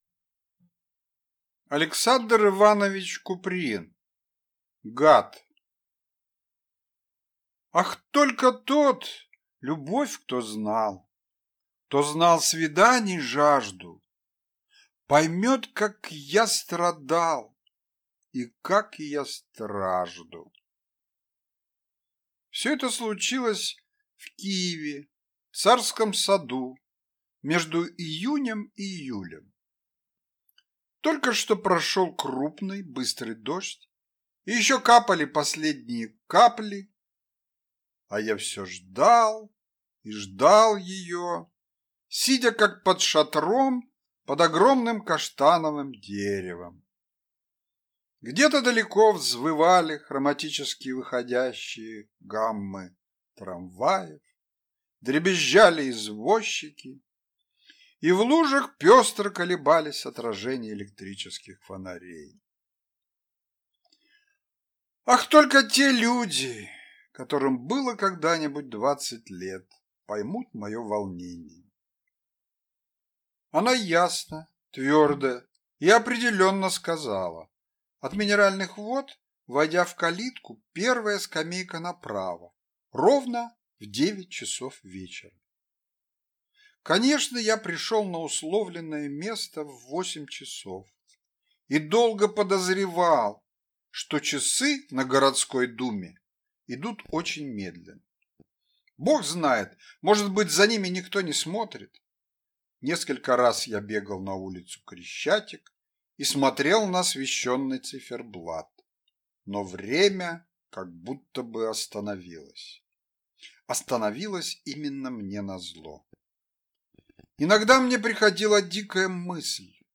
Aудиокнига Гад